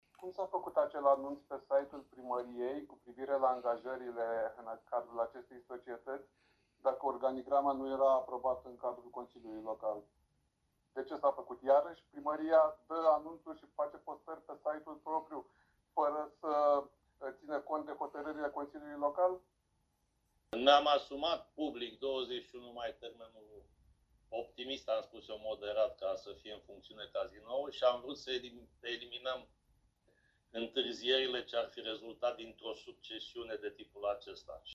Organigrama societății Patrimoniu Constanța Litoral, cea care va administra și exploata Cazinoul, a stârnit cele mai multe discuții în ședința de miercuri a Consiliului Local Constanța.
Liderul aleșilor USR, Florin Cocargeanu, l-a întrebat pe edil de ce Primăria a anunțat că scoate la concurs mai multe posturi, înainte ca organigrama să fie aprobată de Consiliul Local.